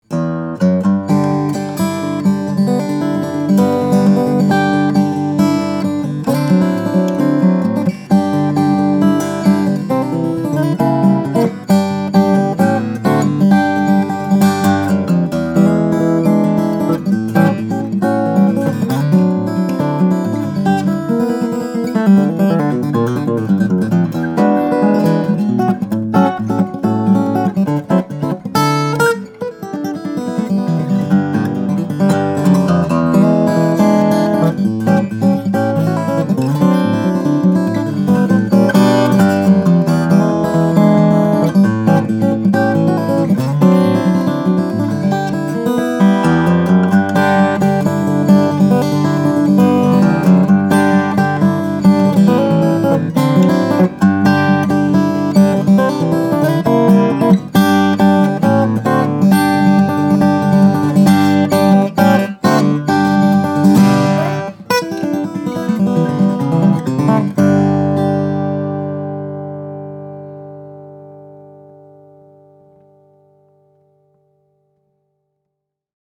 Powerhouse tone and stability like only Carbon Fiber can offer make this guitar a force to be reckoned with.
These guitars have a huge, fierce voice, and thanks to that upper bout soundhole/soundport, the player gets…